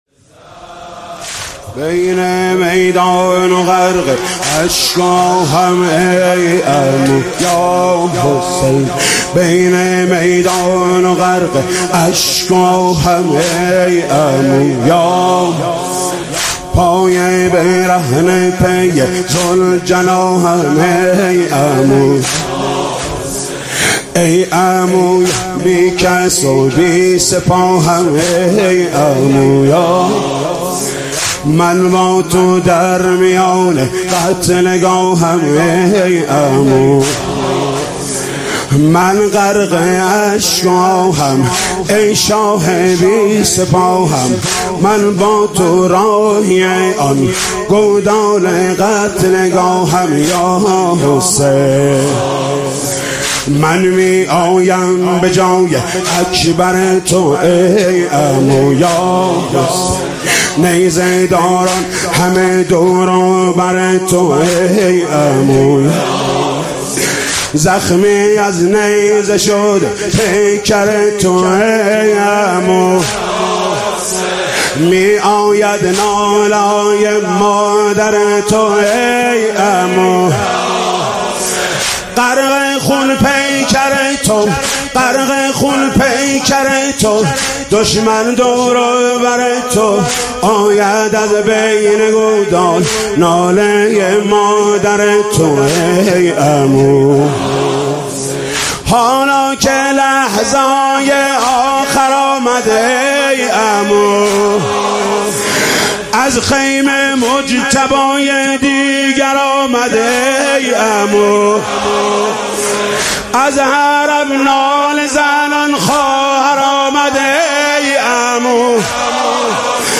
مداحی جدید حاج محمود کريمی شب پنجم محرم97 هيأت راية العباس